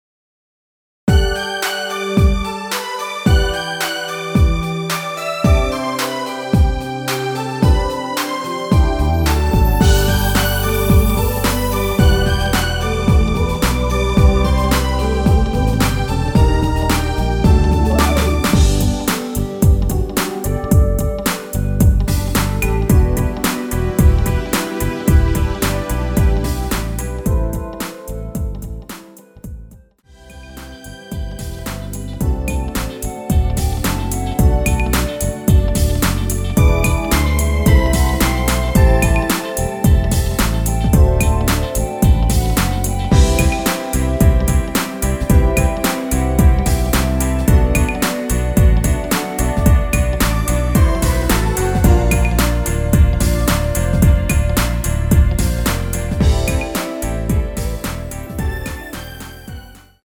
원키 멜로디 포함된 (1절+후렴) MR입니다.
앞부분30초, 뒷부분30초씩 편집해서 올려 드리고 있습니다.
중간에 음이 끈어지고 다시 나오는 이유는